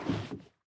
Minecraft Version Minecraft Version 25w18a Latest Release | Latest Snapshot 25w18a / assets / minecraft / sounds / entity / boat / paddle_land2.ogg Compare With Compare With Latest Release | Latest Snapshot
paddle_land2.ogg